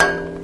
fold and calligraphy sounds
fold.ogg